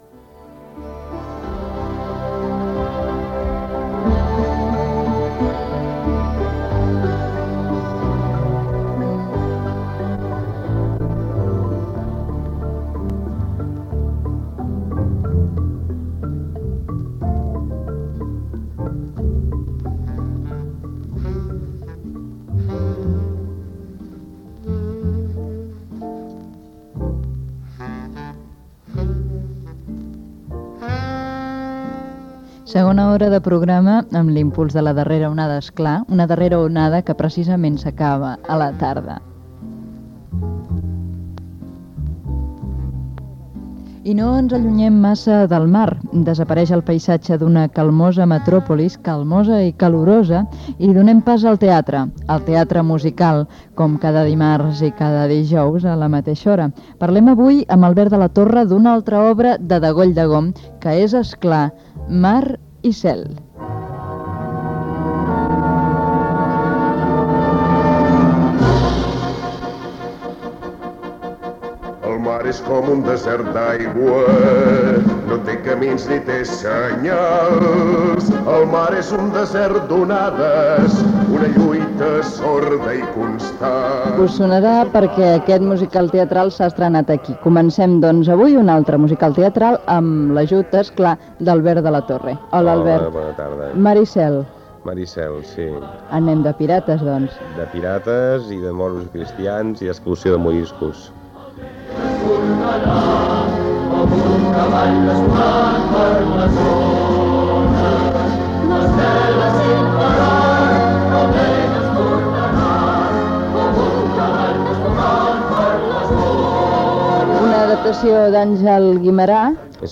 Cadena Catalana Barcelona
Entreteniment